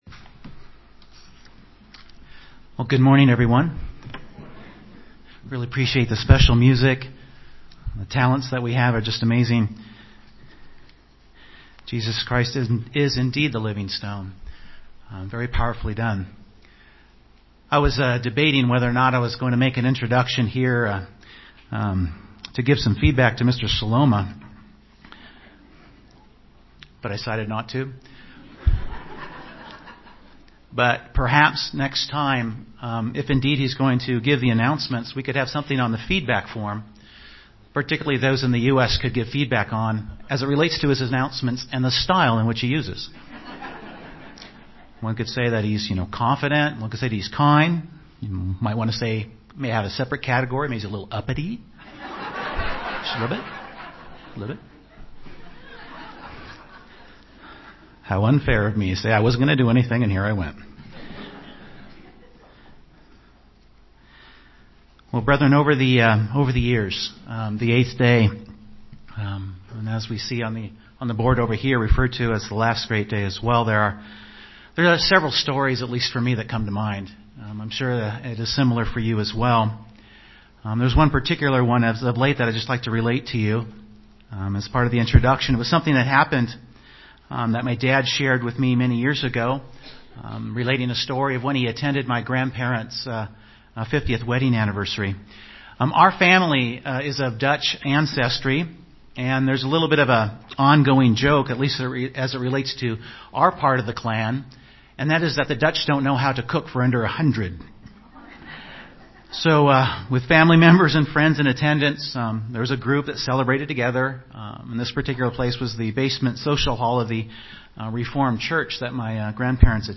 This sermon was given at the Canmore, Alberta 2015 Feast site.